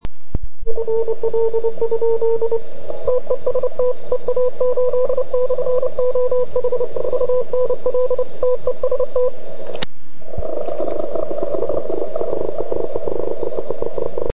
Jak poslouchala FT857?